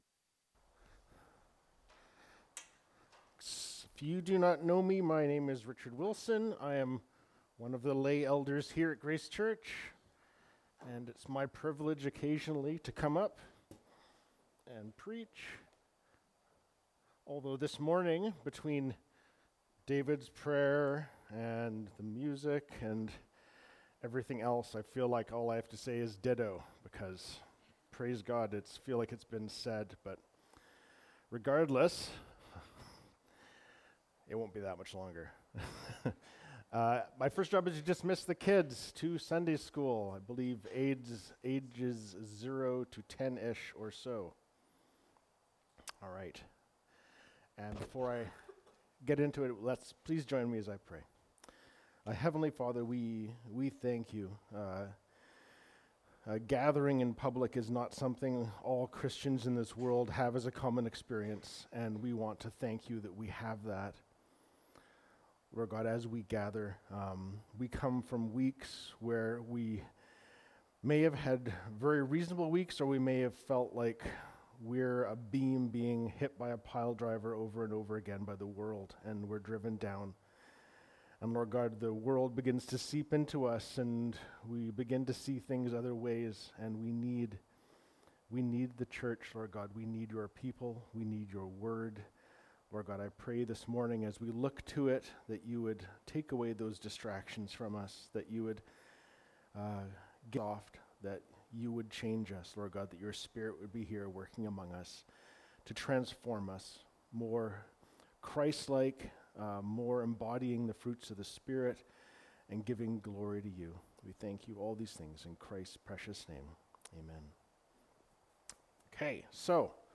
Apr 21, 2024 God’s Glory (John 17:1-8) MP3 SUBSCRIBE on iTunes(Podcast) Notes Discussion Sermons in this Series This sermon was recorded in Grace Church Salmon Arm and preached in Enderby.